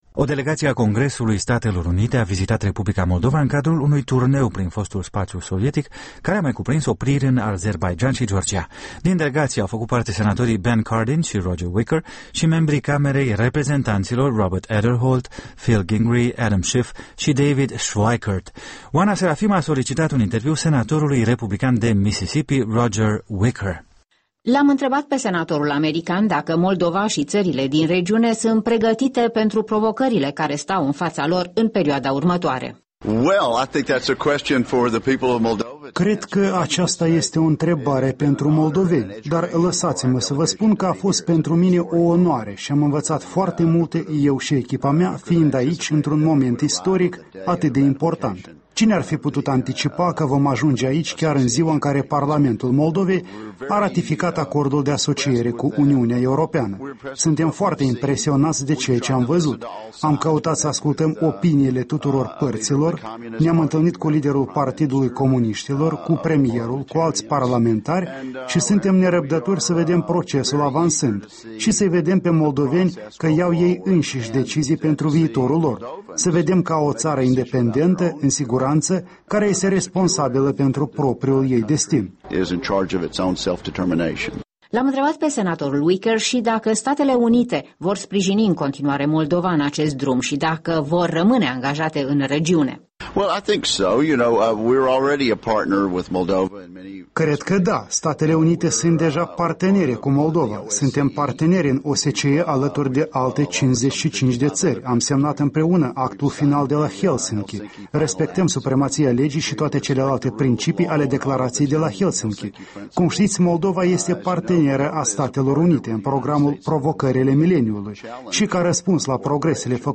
Interviul acordat Europei Libere de senatorul american Roger Wicker